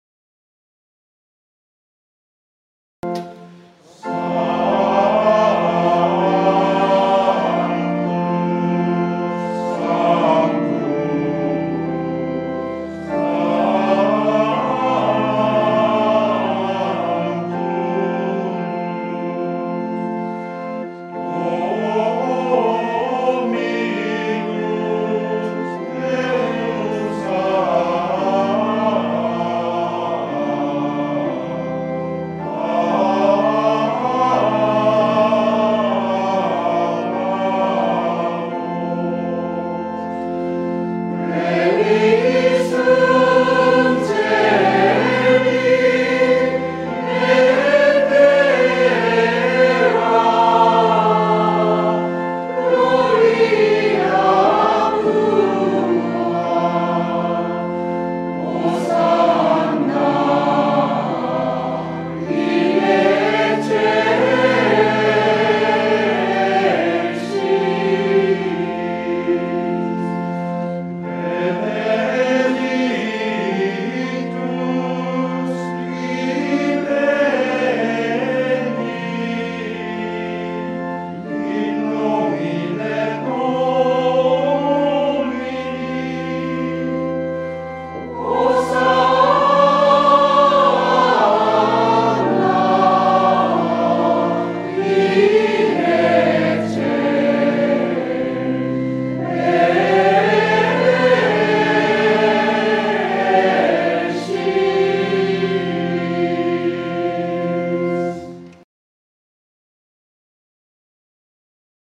Mass of the Angels, Gregorian Chant